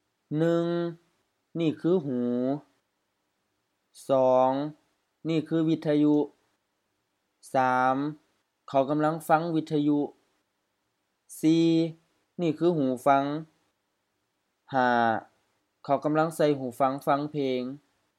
IsaanPronunciationTonesThaiEnglish/Notes